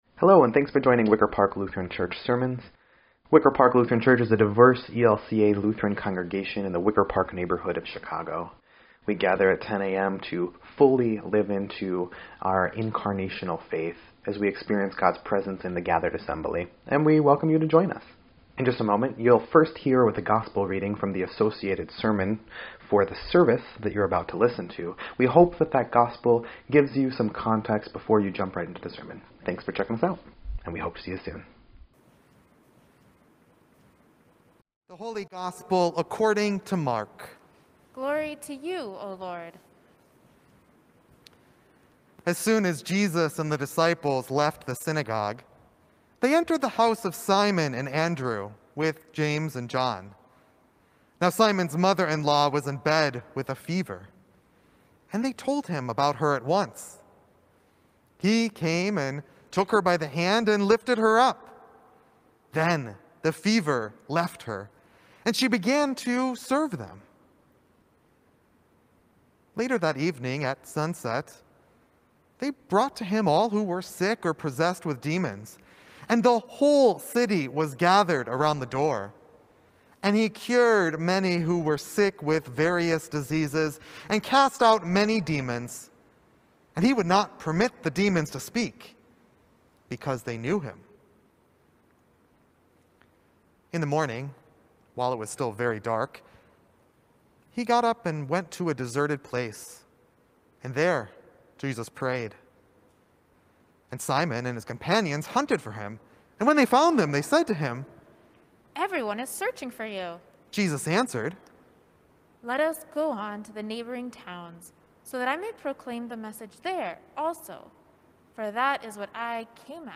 2.7.21-Sermon_EDIT.mp3